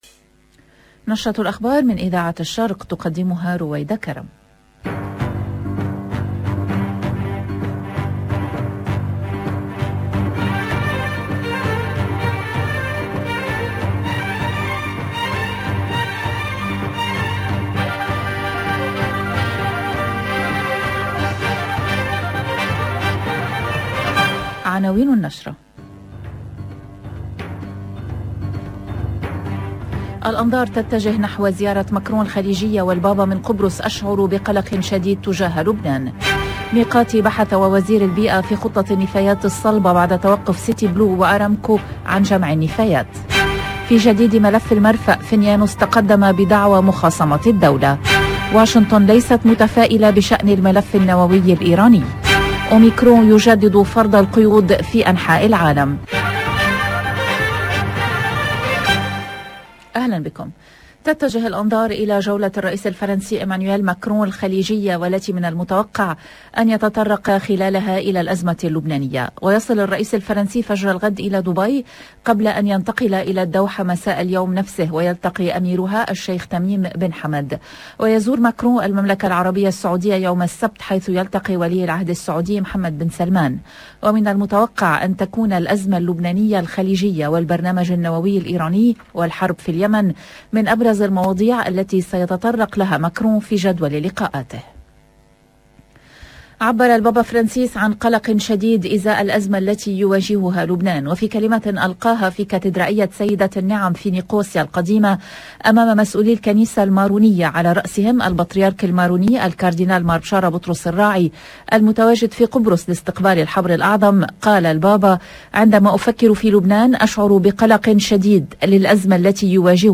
EDITION DU JOURNAL DU SOIR EN LANGUE ARABE DU 2/12/2021